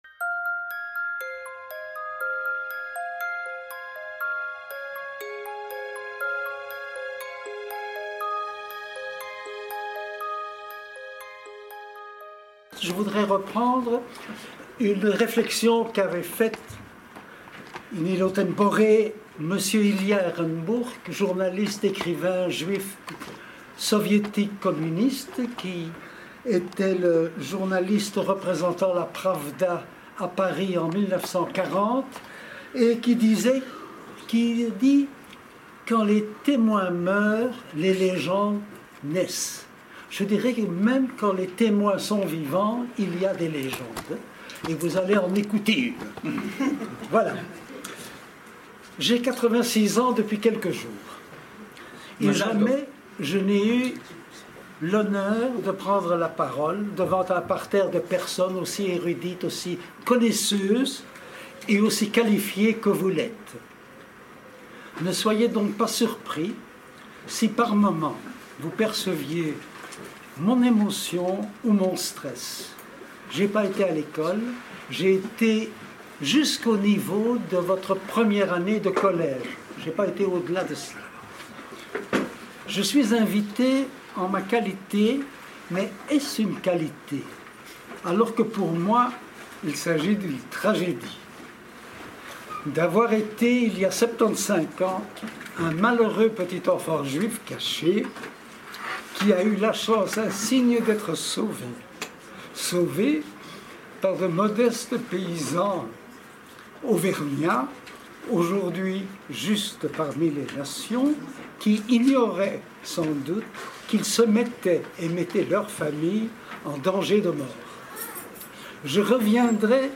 Il livre ici le récit de sa propre histoire et des réflexions plus générales sur la Shoah, et, au-delà, sur l’Humanité. Un témoignage émouvant, indispensable.